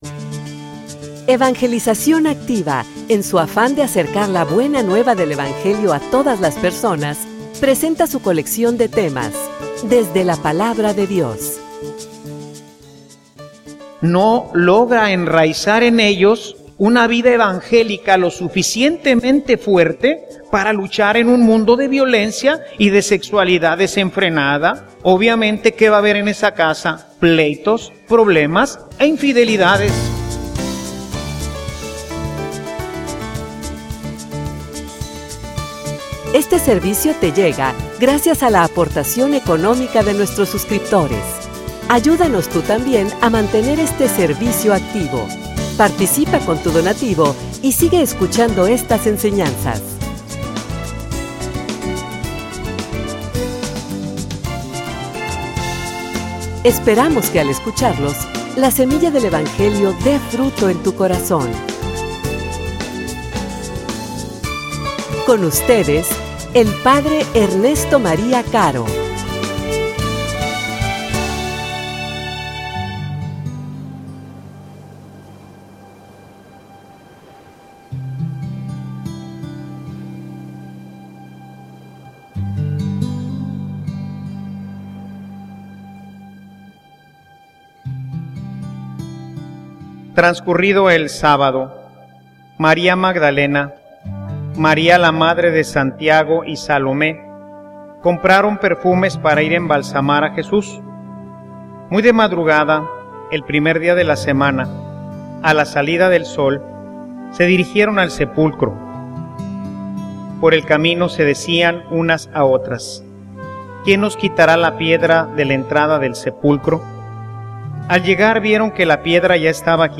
homilia_Tiempo_de_anunciar_el_evangelio.mp3